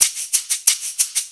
Shaker 06.wav